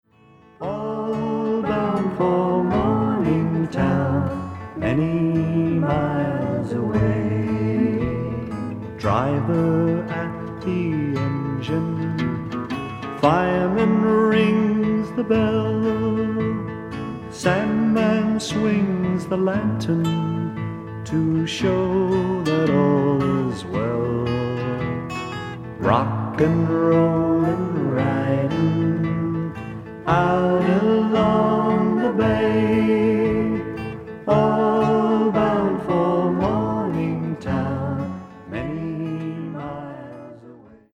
Nursery rhymes and other songs for little folk.
Vocal